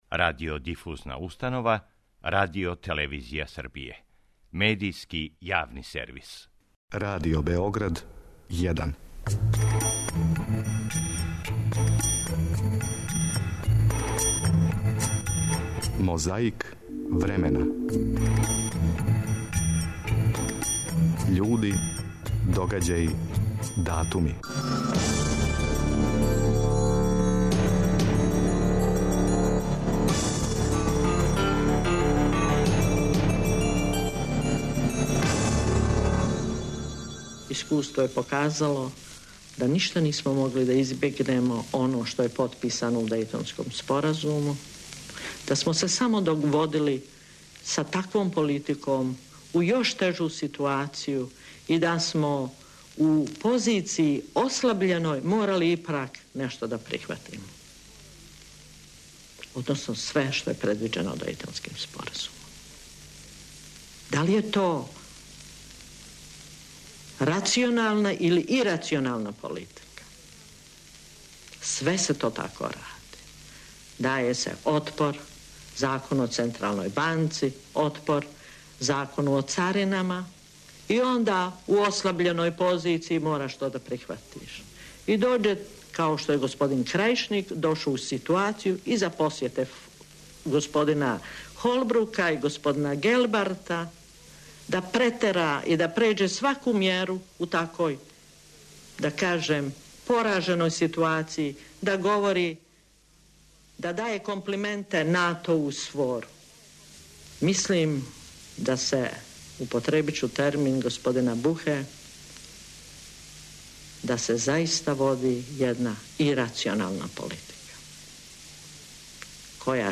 Путовање кроз време почињемо подсећањем на обраћање Биљане Плавшић, путем ТВ Бања Лука, 11. августа 1997. године.
У име репрезентативаца, говорио је капитен Александар Саша Ђорђевић.